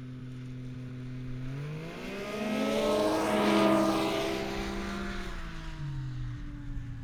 Snowmobile Description Form (PDF)
Subjective Noise Event Audio File - Run 4 (WAV)